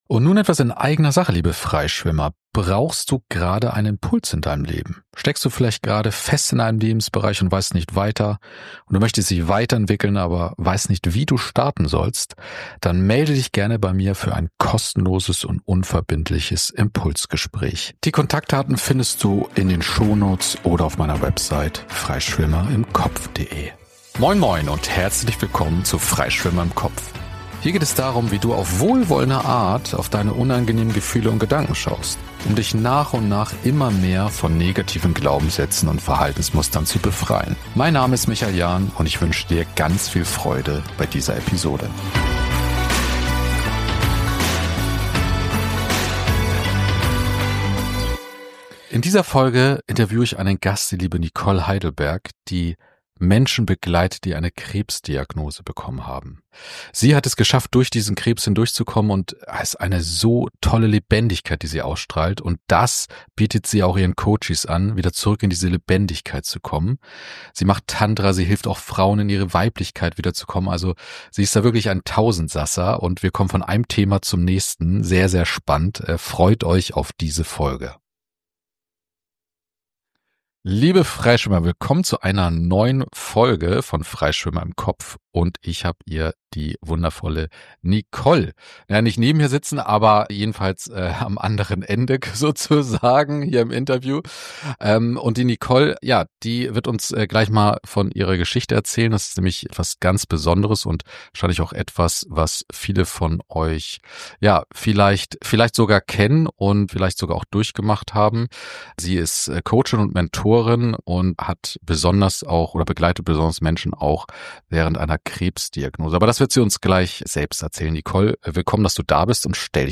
041: Die Krebsdiagnose ist der Anfang eines neuen, glücklichen Lebens - im Gespräch